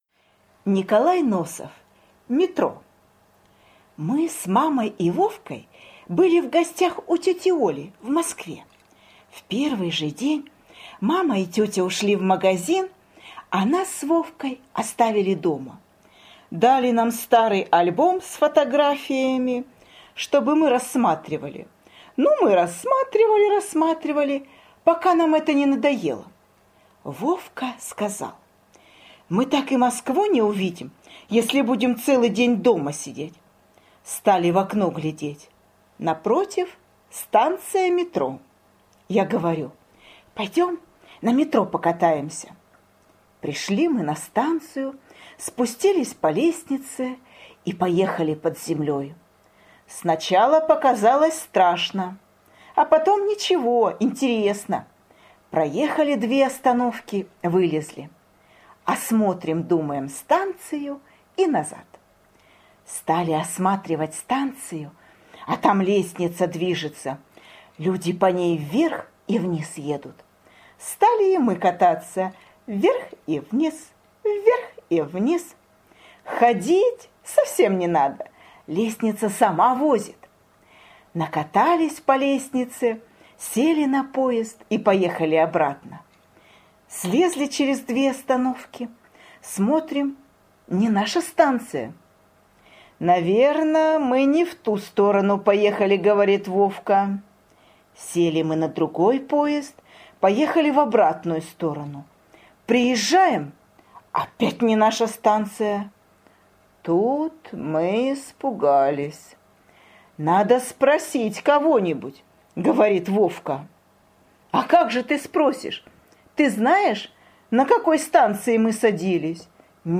Аудиорассказ «Метро»